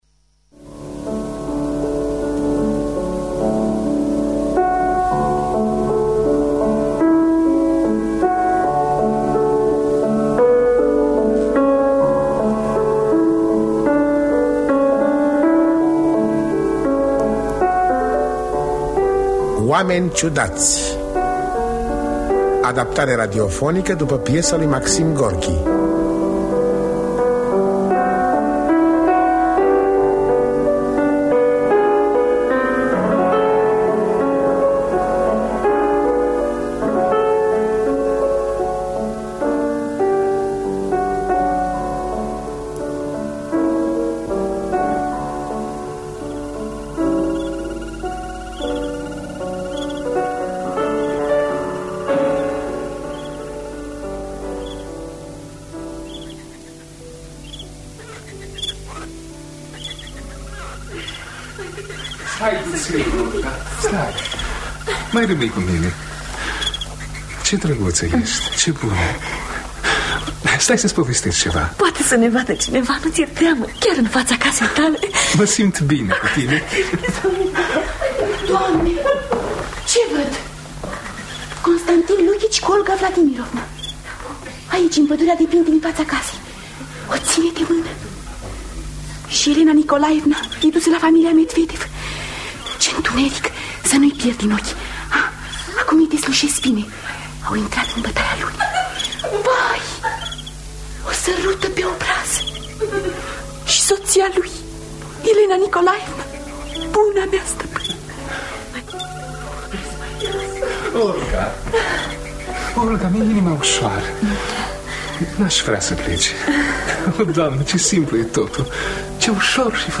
Adaptarea radiofonică
Înregistrare din anul 1958 (30 martie).